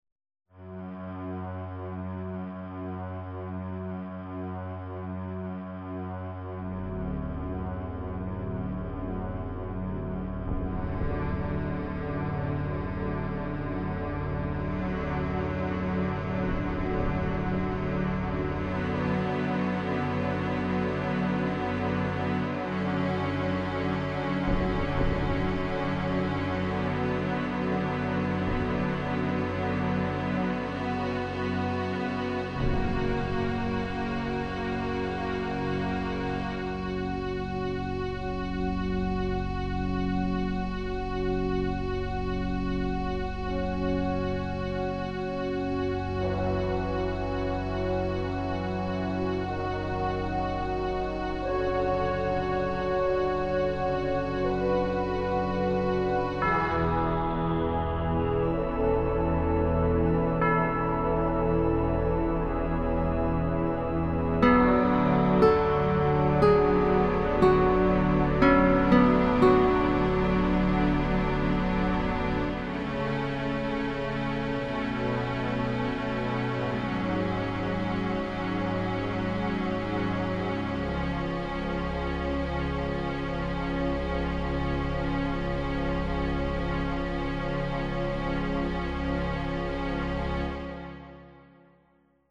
Sintonía del CD-Rom interactivo sobre